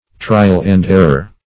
trial-and-error - definition of trial-and-error - synonyms, pronunciation, spelling from Free Dictionary